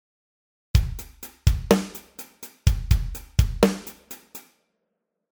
穏やかなJ-POPなどでありがち
ドッッド　タッッッ　ドドッド　タッッッのパターンです。